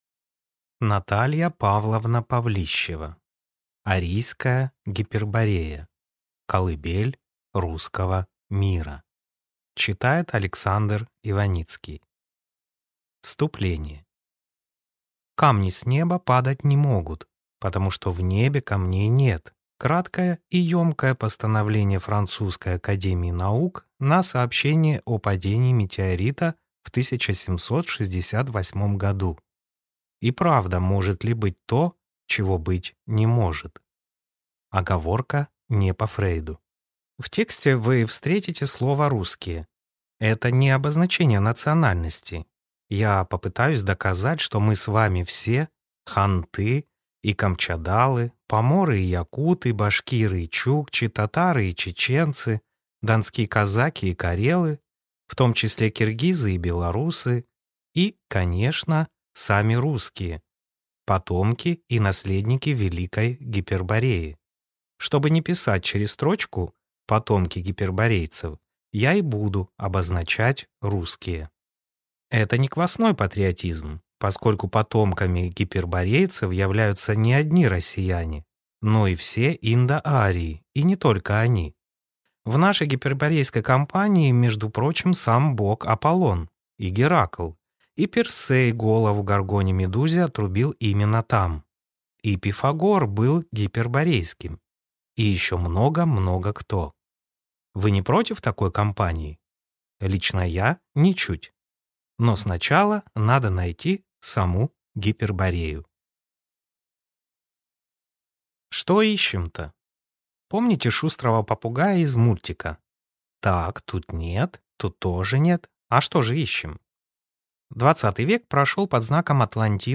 Аудиокнига Арийская Гиперборея. Колыбель Русского Мира | Библиотека аудиокниг